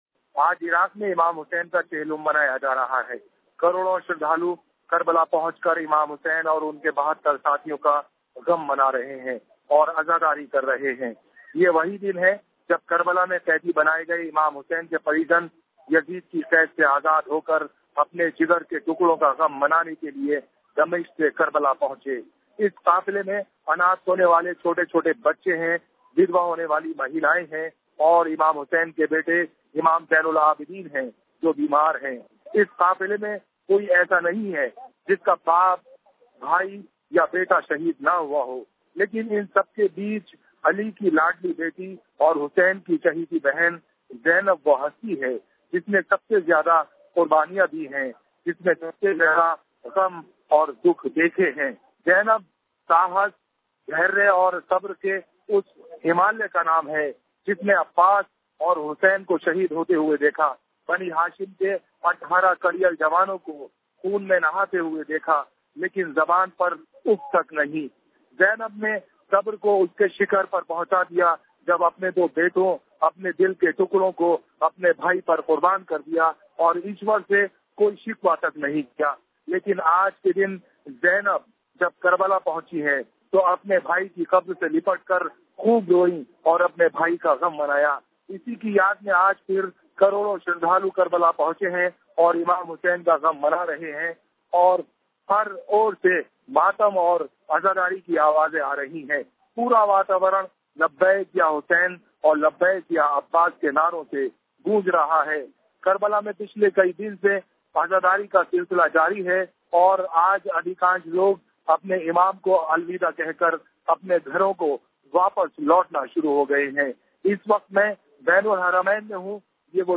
हज़रत इमाम हुसैन अलैहिस्सलाम के चेहलुम की रिपोर्टिंग करने कर्बला गये हमारे साथी की विशेष रिपोर्ट